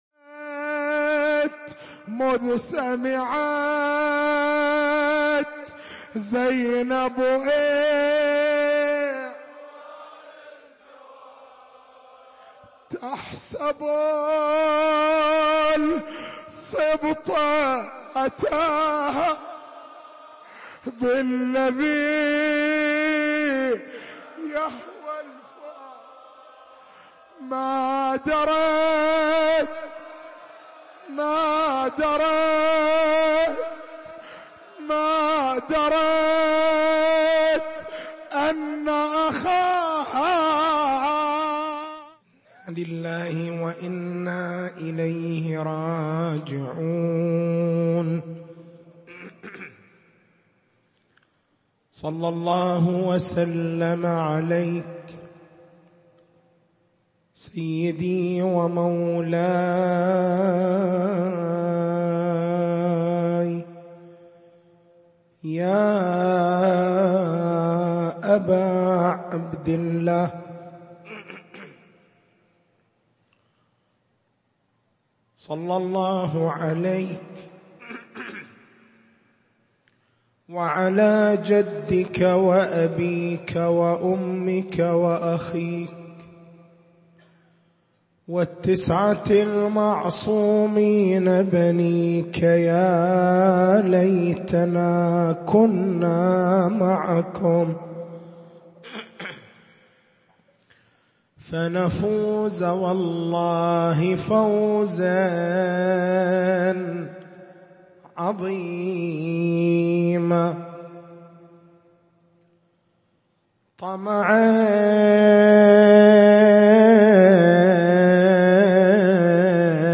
تاريخ المحاضرة: 12/01/1439 نقاط البحث: هل الدين الإسلاميّ هو دين السماحة؟
حسينية الزين بالقديح